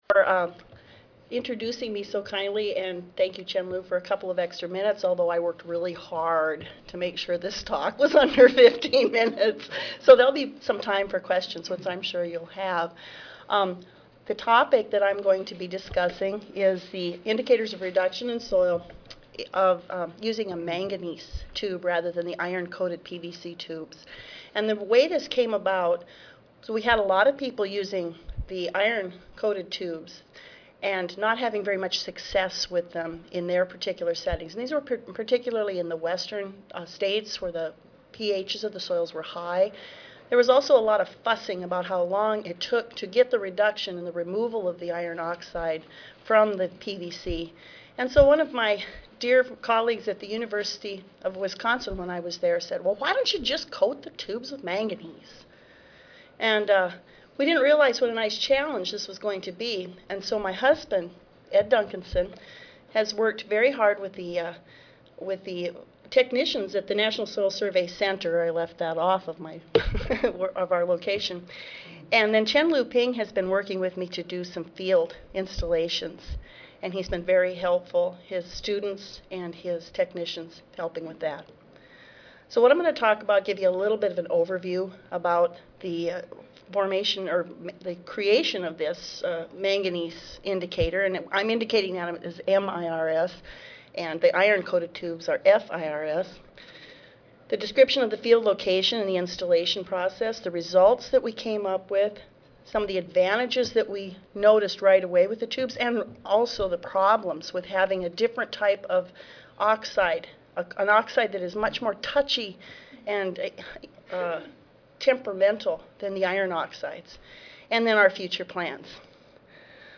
S10 Wetland Soils Session: General Wetland Soils: II (2010 Annual Meeting (Oct. 31 - Nov. 3, 2010))
USDA-NRCS National Soil Survey Center Audio File Recorded presentation